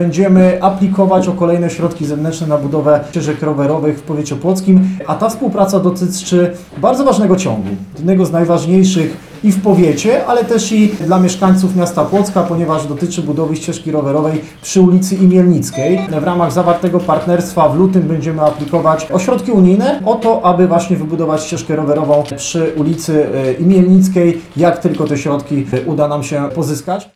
– mówił Starosta Płocki Sylwester Ziemkiewicz podczas konferencji prasowej.